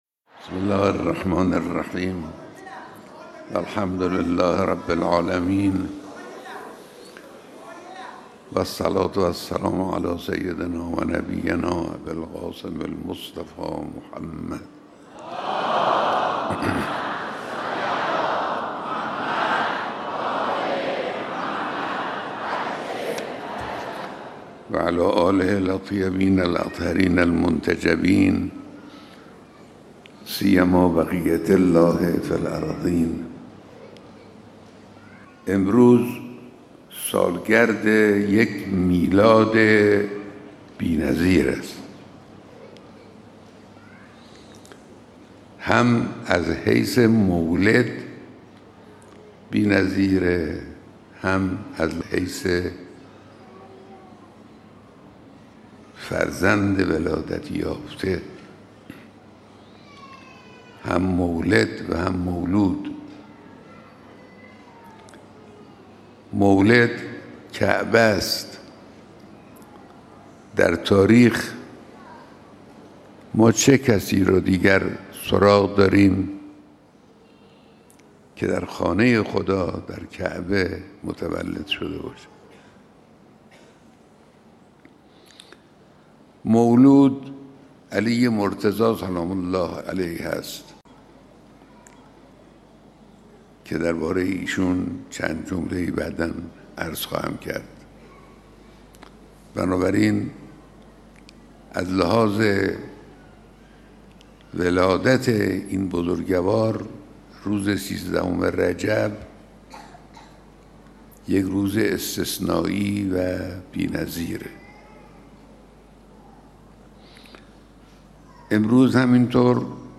بیانات رهبر انقلاب در میلاد امیرالمؤمنین و سالگرد شهید حاج قاسم سلیمانی - ۱۳ دی ۱۴۰۴
در روز میلاد حضرت امیرالمؤمنین علیه‌السلام و همزمان با سالگرد شهادت سردار سپهبد حاج قاسم سلیمانی، رهبر معظم انقلاب اسلامی حضرت آیت الله امام خامنه ای (مدظله العالی) در تاریخ ۱۳ دی ۱۴۰۴ دیداری با خانواده شهید سلیمانی و یاران ایشان داشتند.